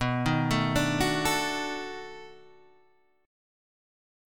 B7#9 chord {7 6 7 7 7 5} chord